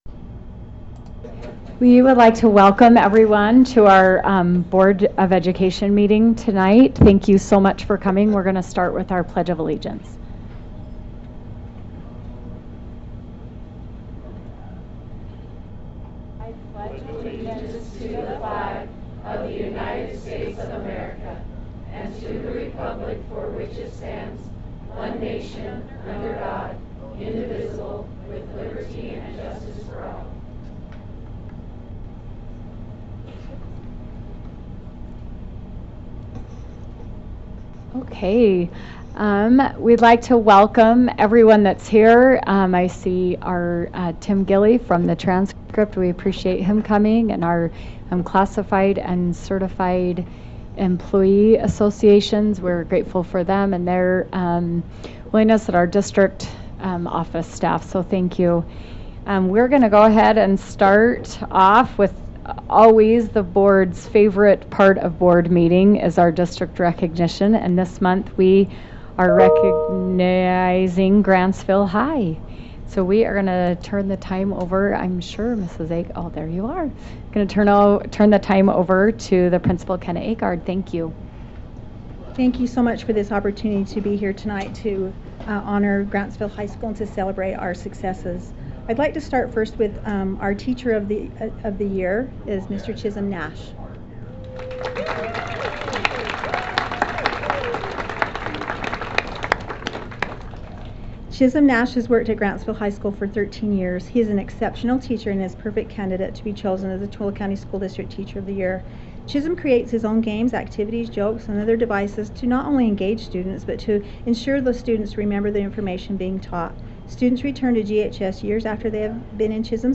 March 12, 2024 Board of Education Meeting